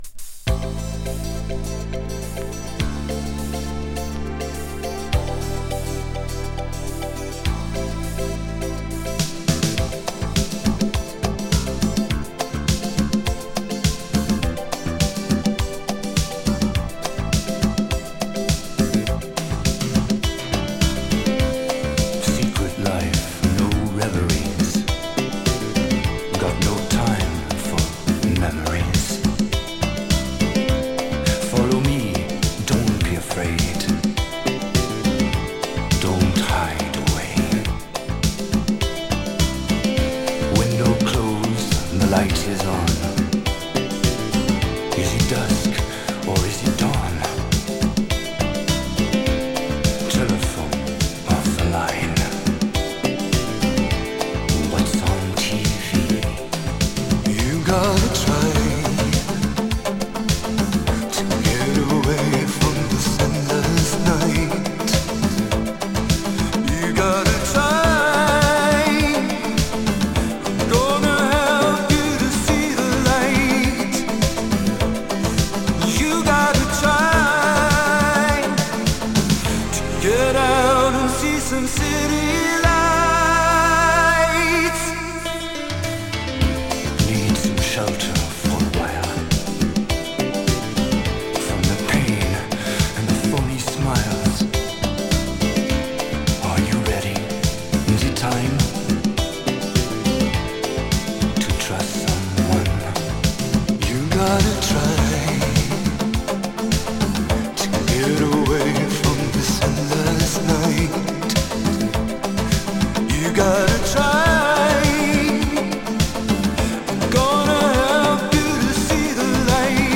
media(A/B): VG/VG Very slight dust noise
An American singer based in Europe.
[ITALO DISCO]